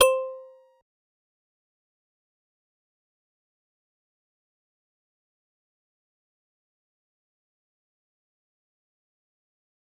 G_Musicbox-C5-pp.wav